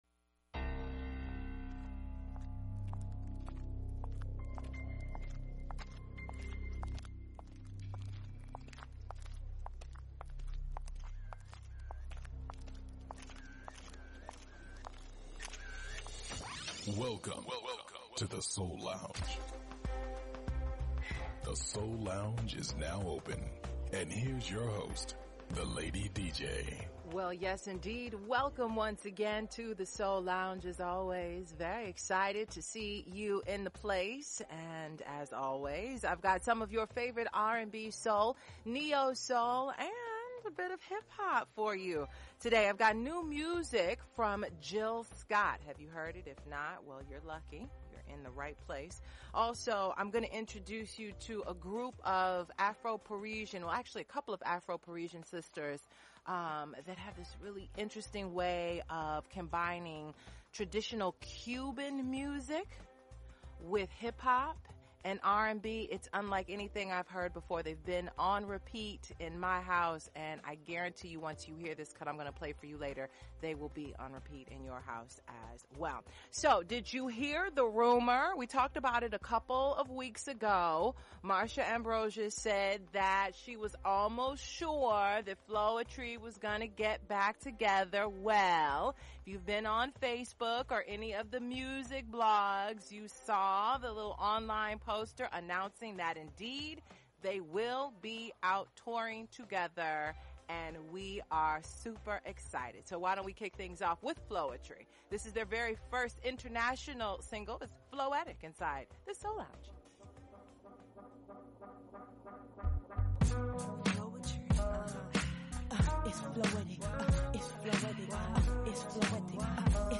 Neo-Soul
conscious Hip-Hop